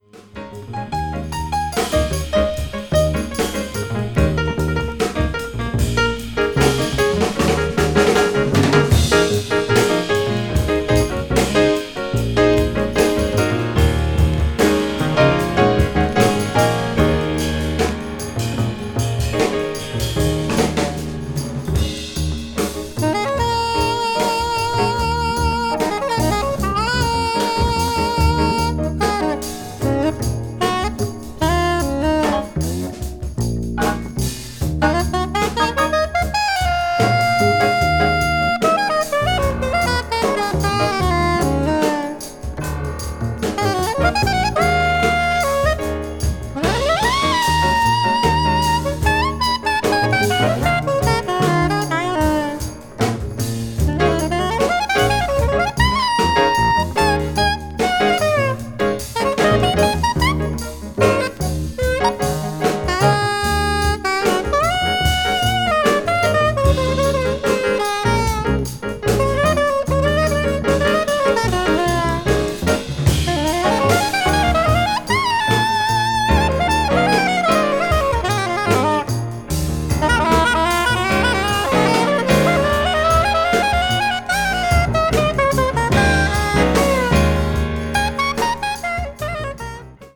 media : EX-/EX-(薄いスリキズによるわずかなチリノイズが入る箇所あり)